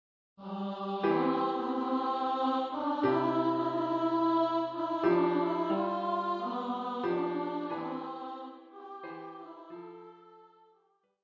für mittlere Stimme